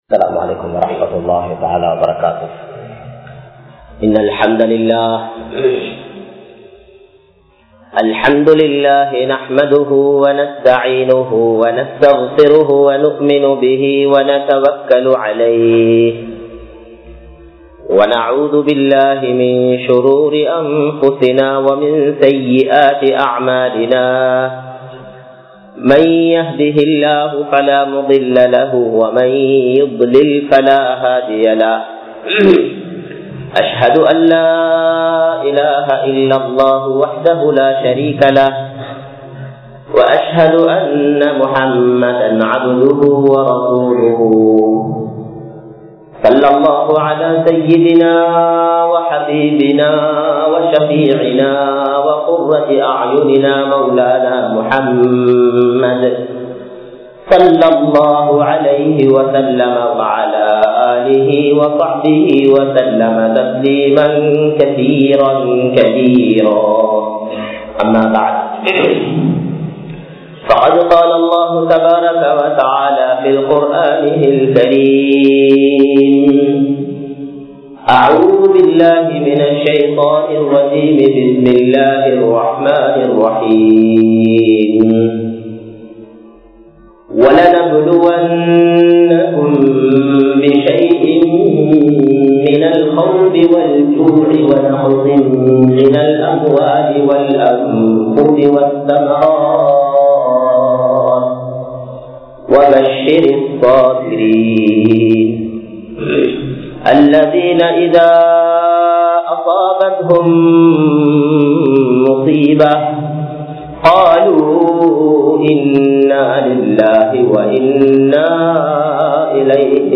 Allah`vukkaaha Maaththiram Uthavungal (அல்லாஹ்வுக்காக மாத்திரம் உதவுங்கள்) | Audio Bayans | All Ceylon Muslim Youth Community | Addalaichenai